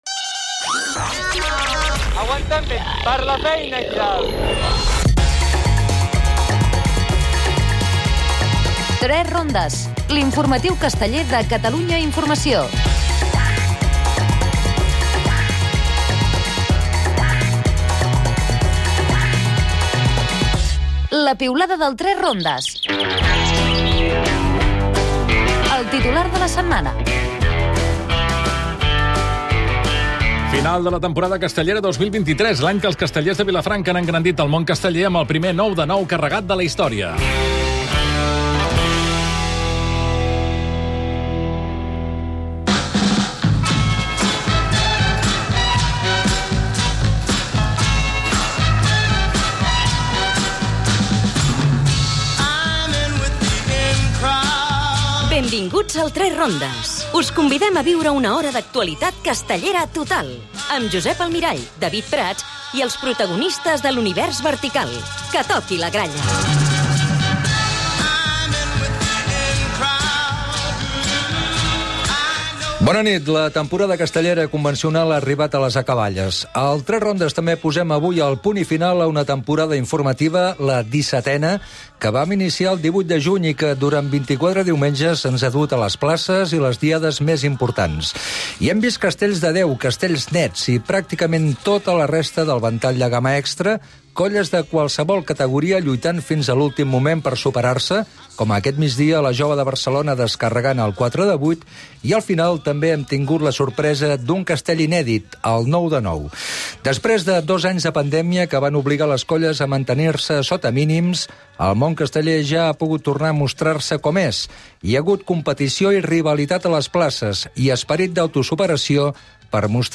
El "3 Rondes" ha estat en la diada de la festa major de la Sagrera.